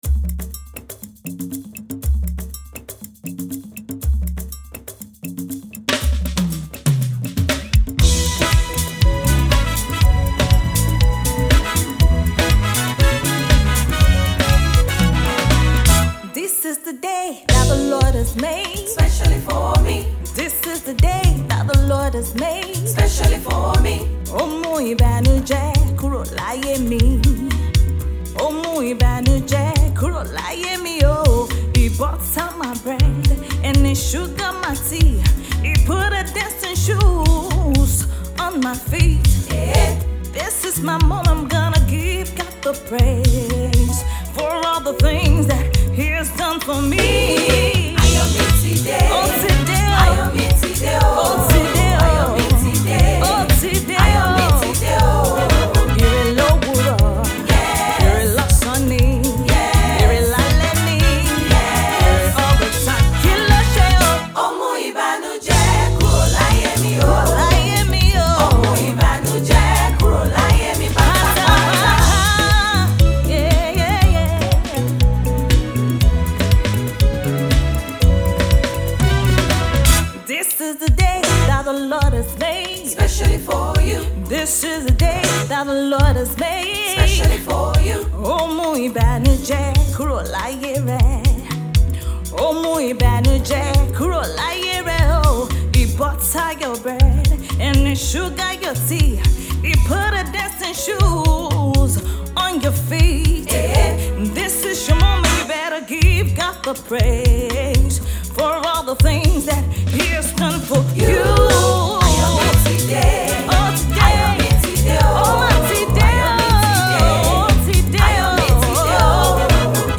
praise and worship
enchanting rendition of this Gospel tune